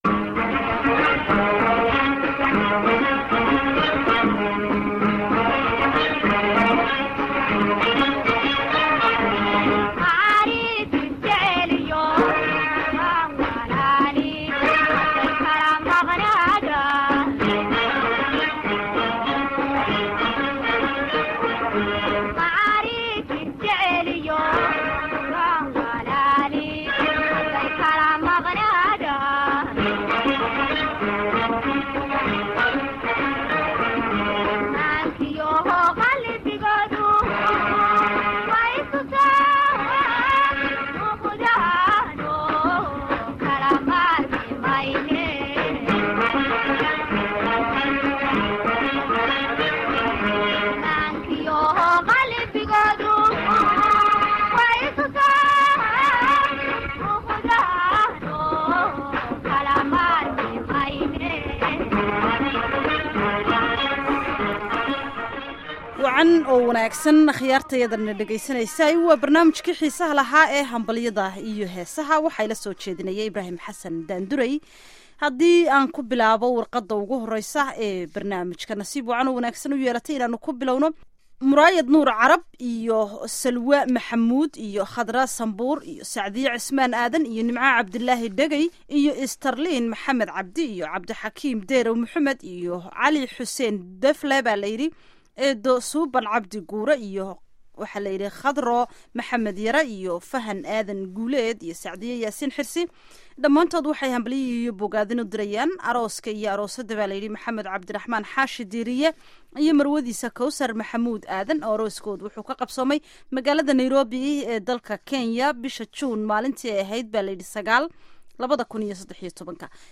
Barnaamijka Hanbalyada iyo Heesaha waxaan idinkugu soo gudbinaa salamaaha iyo hanbalyada ay asaxaabtu isu diraan, iyo dabcan heeso dabacsan oo isugu jira kuwii hore, dhexe iyo kuwa cusub.